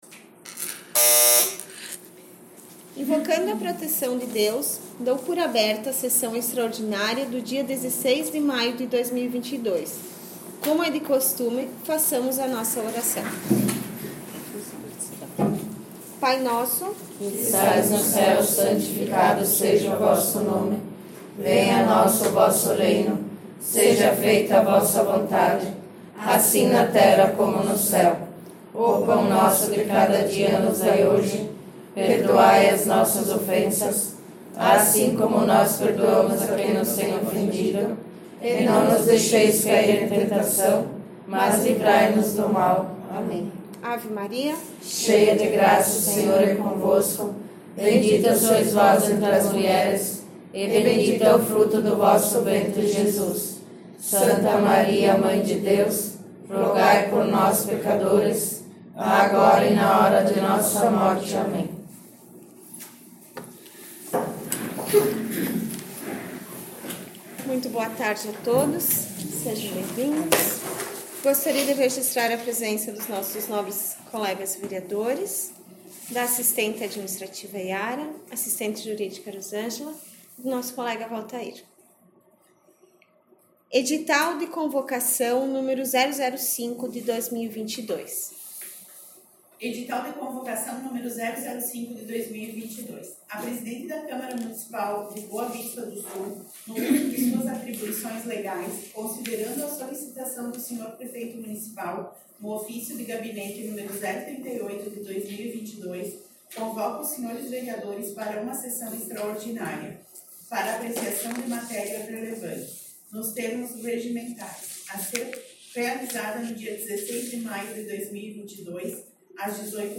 13 - Sessão Extraordinária 16 maio